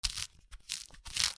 みかんの皮をむく
『ミシミシ』